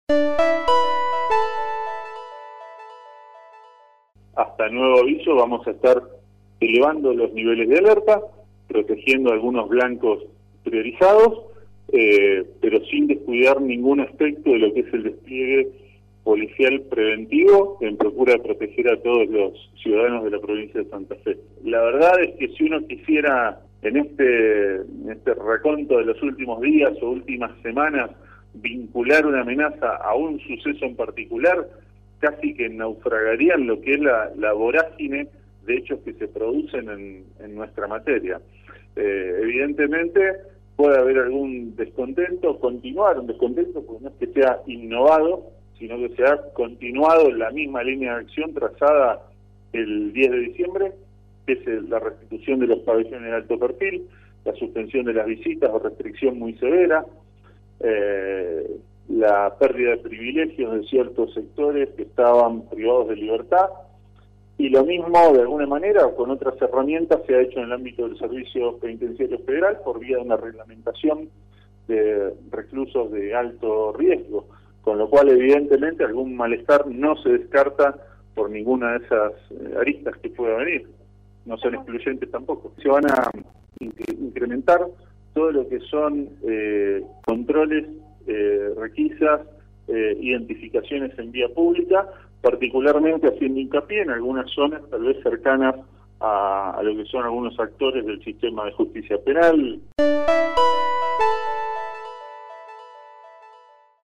En contacto con el móvil de LT3 el Ministro de Seguridad de Santa Fe, Pablo Cococcioni, dijo que se incrementarán los controles y las requisas en las calles.